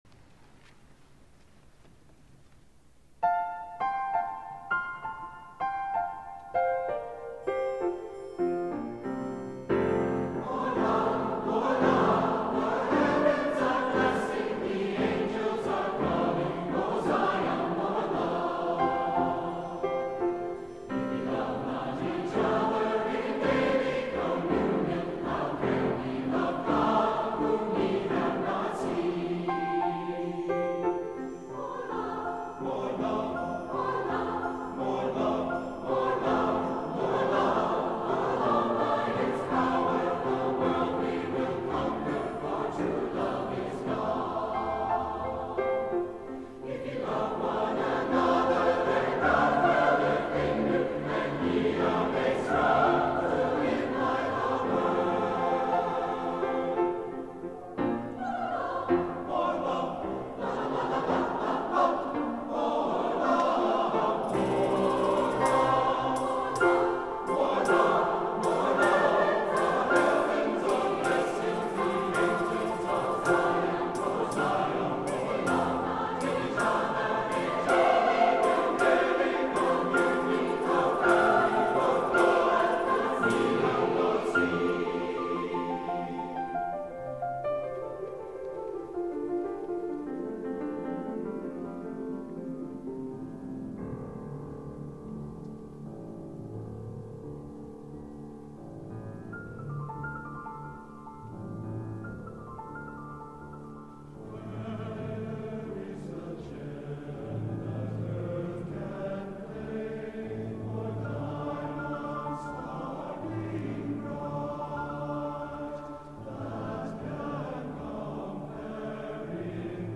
for SATB Chorus and Piano (2006)